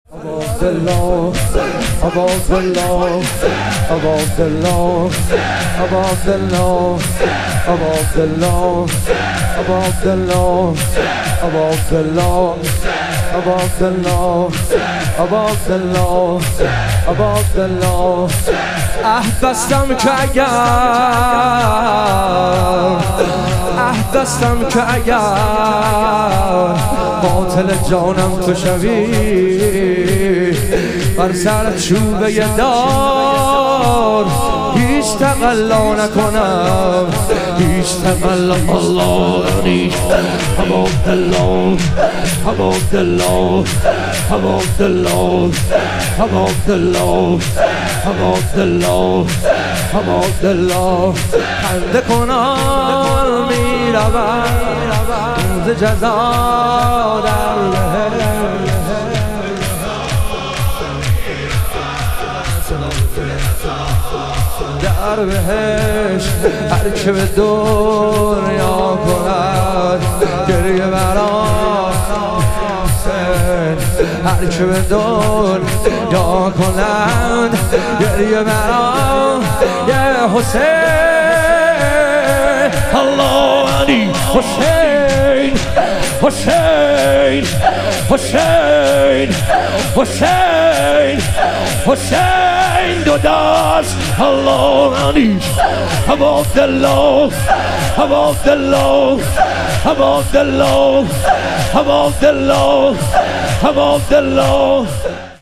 ایام فاطمیه دوم - شور